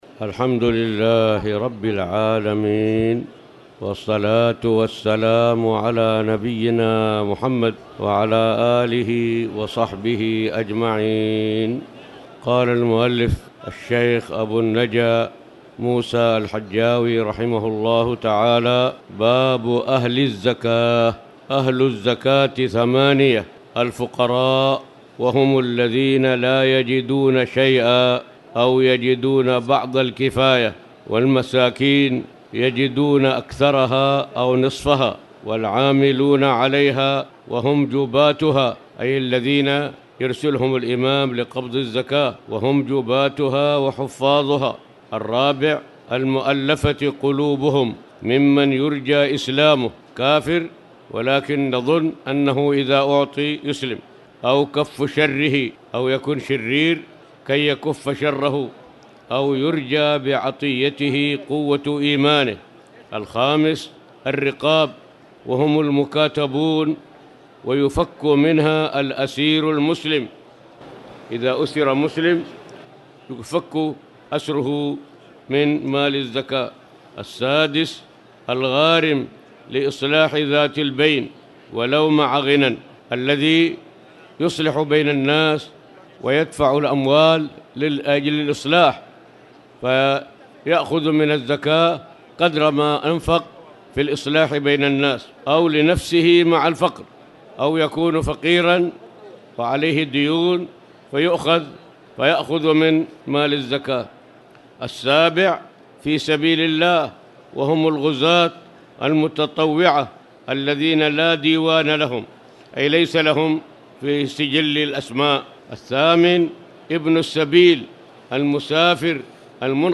تاريخ النشر ٢٥ رمضان ١٤٣٨ هـ المكان: المسجد الحرام الشيخ